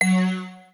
UIClick_Mallet Tonal Long 04.wav